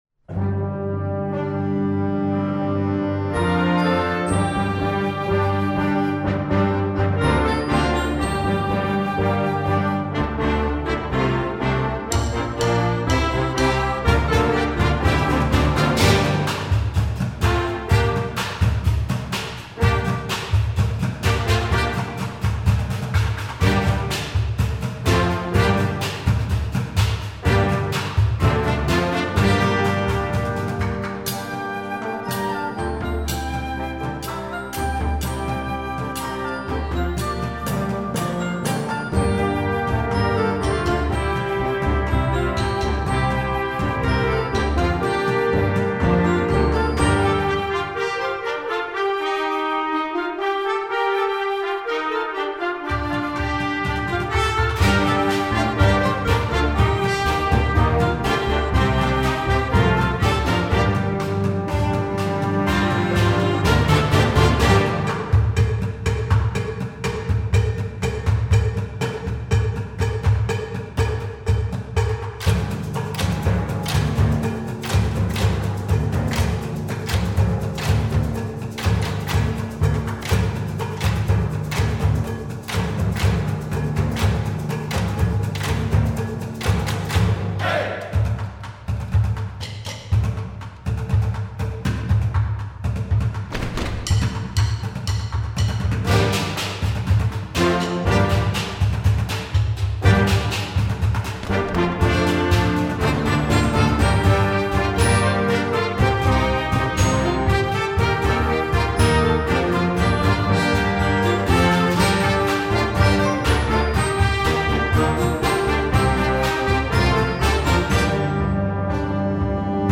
Voicing: Percussion w/ Band